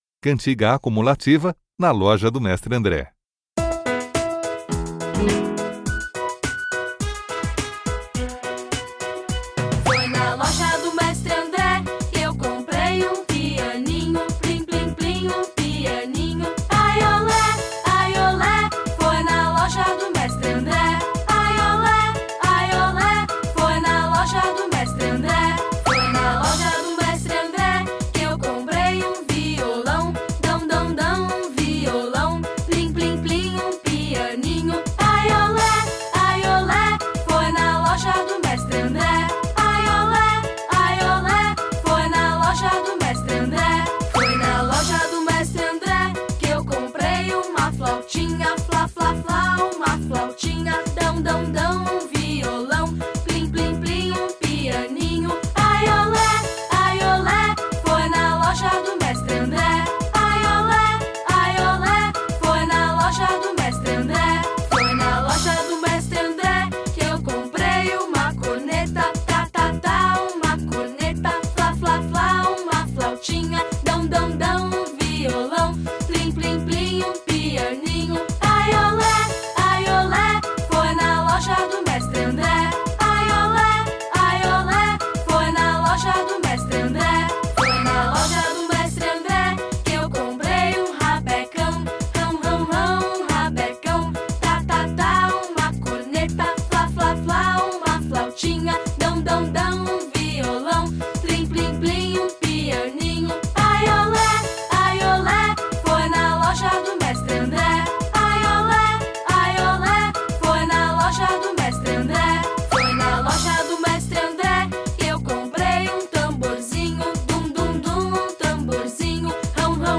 Cantiga acumulativa "Na loja do Mestre André"